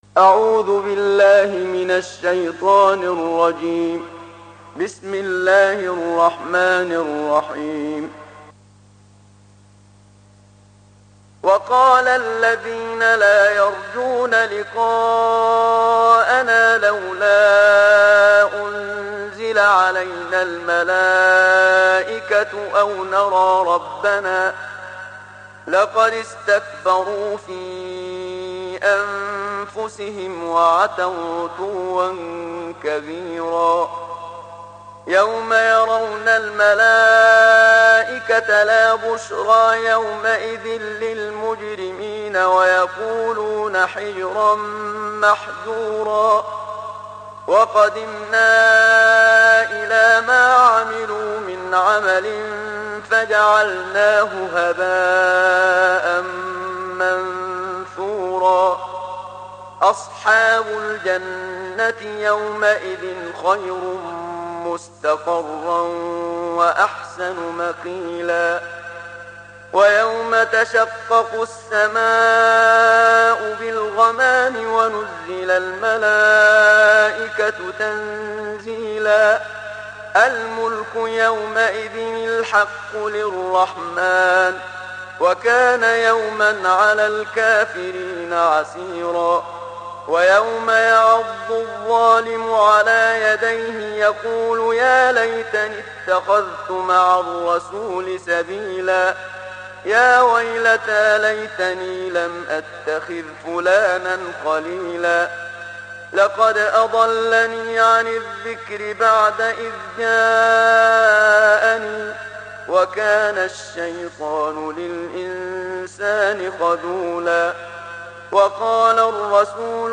صوت/ ترتیل جزء نوزدهم قرآن مجید با صدای استاد منشاوی